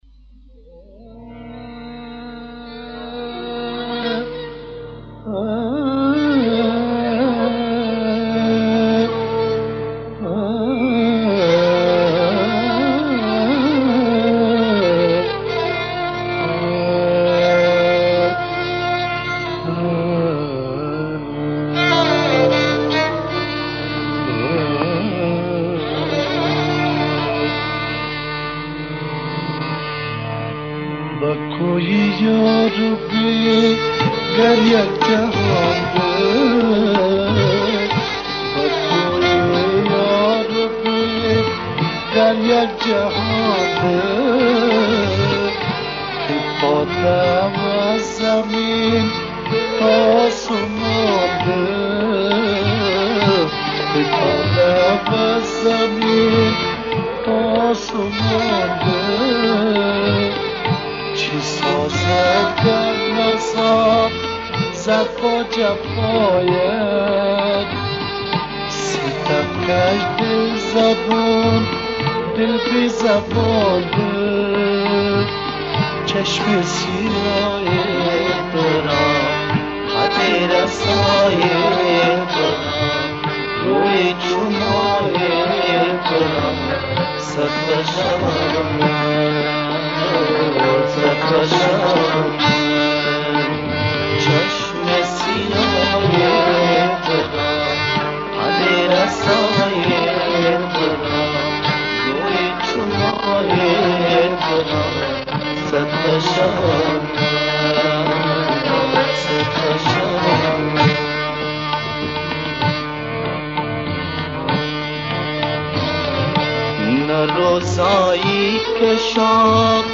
آهنگی دوگانه است
طبله‌نواز
رباب‌نواز
فلوت‌نواز